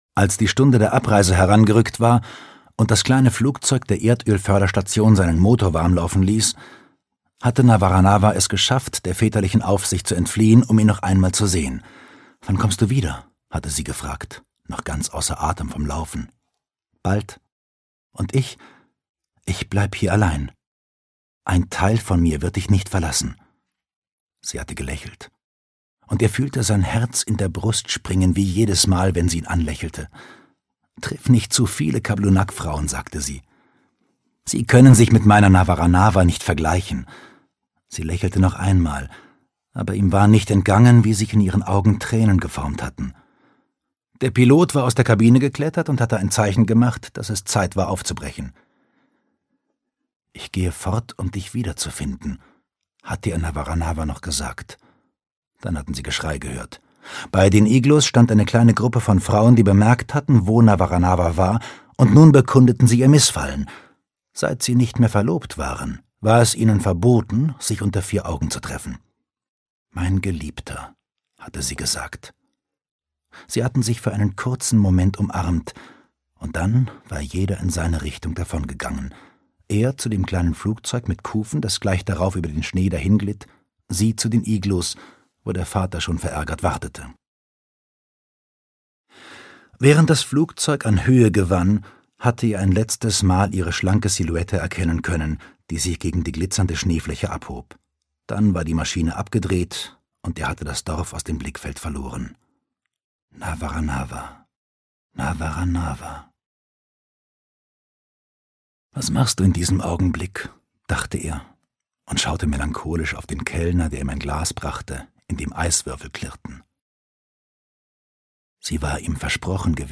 Im Durcheinanderland der Liebe - François Lelord - Hörbuch